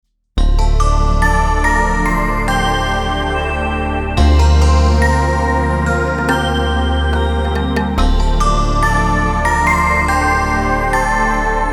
Накрутить колокольчики (?)